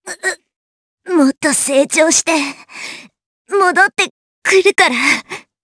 Cecilia-Vox_Dead_jp.wav